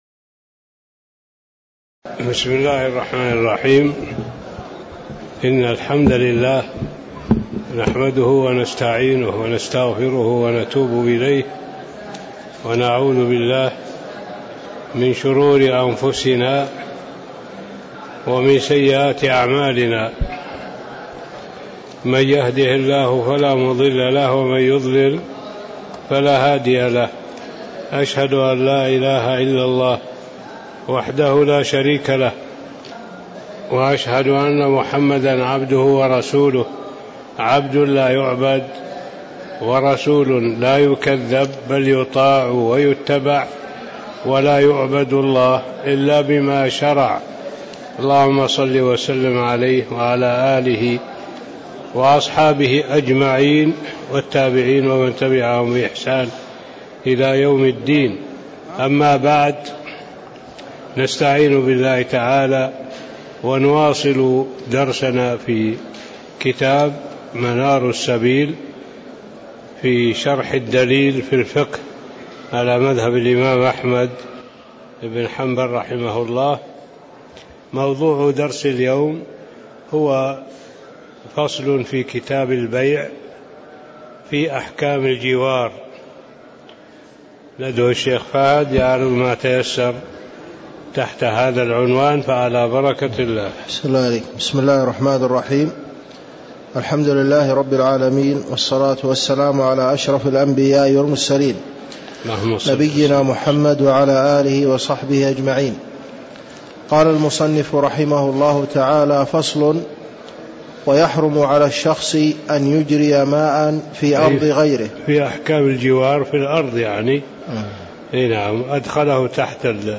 تاريخ النشر ٢ صفر ١٤٣٧ هـ المكان: المسجد النبوي الشيخ